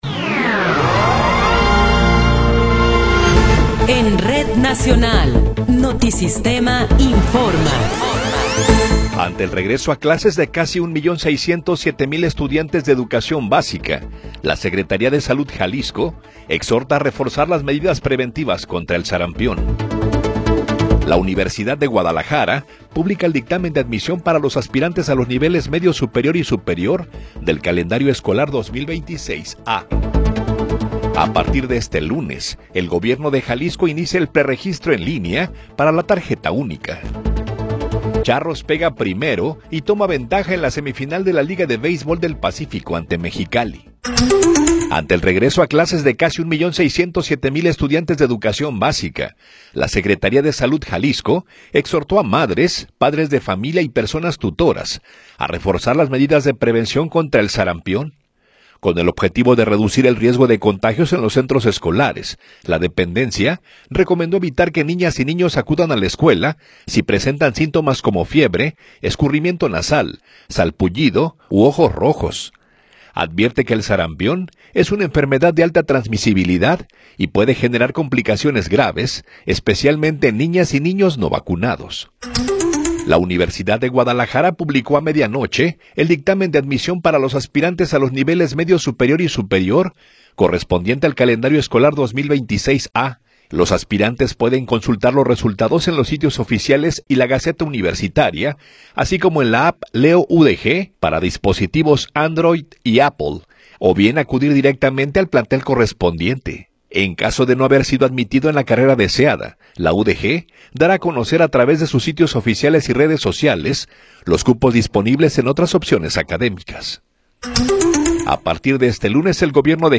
Noticiero 9 hrs. – 12 de Enero de 2026